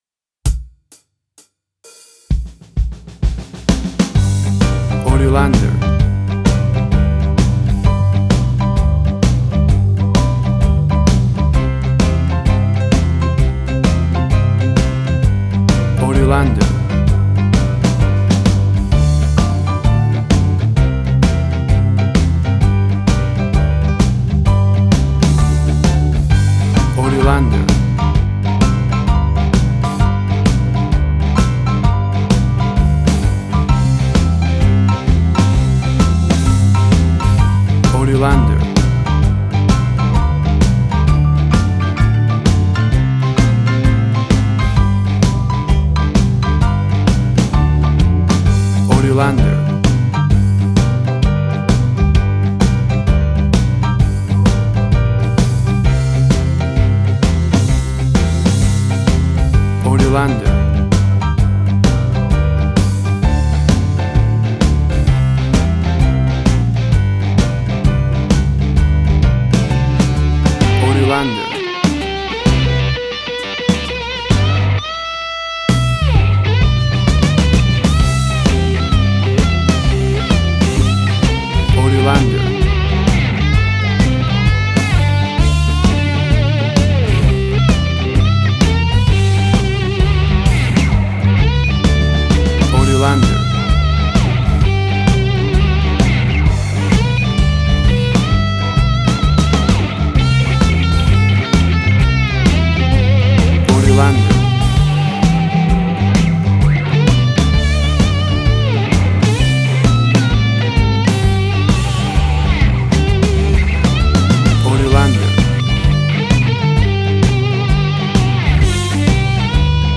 Tempo (BPM): 129